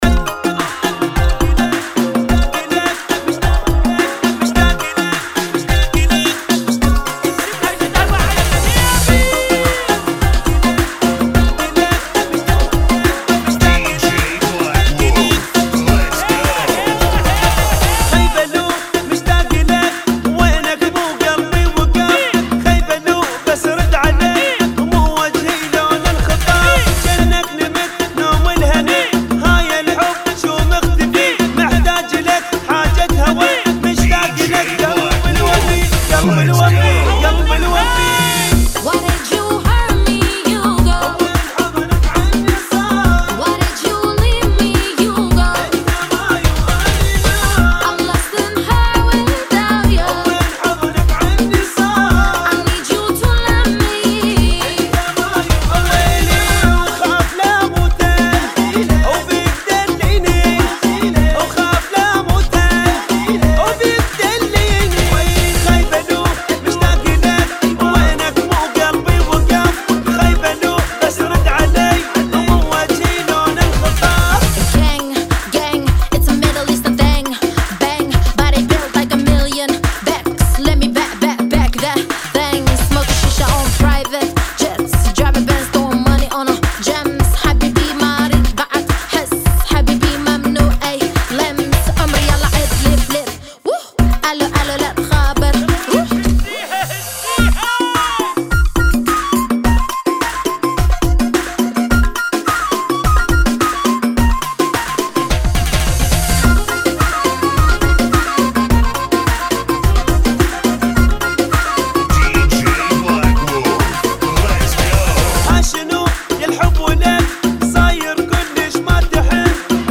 [ 106 BPM ]